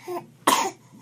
fixed baby sounds
cough.wav